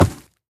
25w18a / assets / minecraft / sounds / mob / piglin_brute / step5.ogg